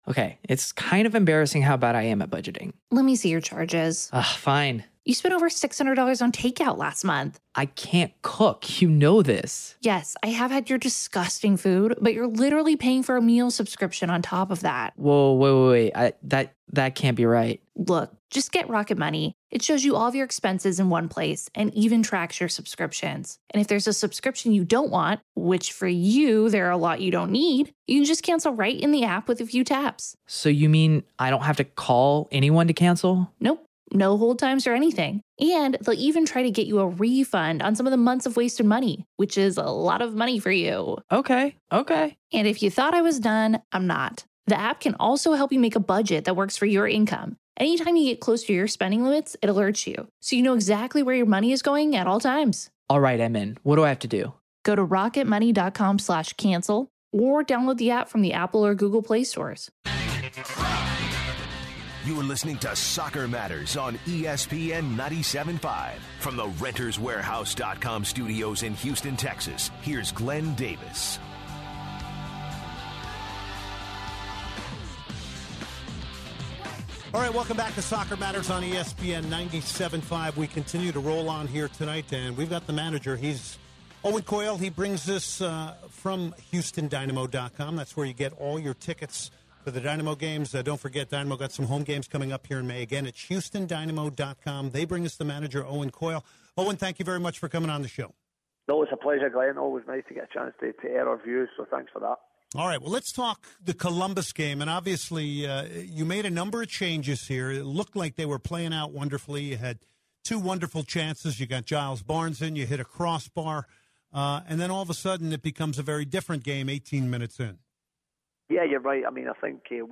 The second hour is interview heavy with Dynamo manager Owen Coyle talking about how the Dynamo will turn things around.